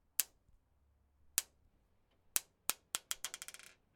Marble Bouncing
ball bounce clank clink clonk ding glass hollow sound effect free sound royalty free Sound Effects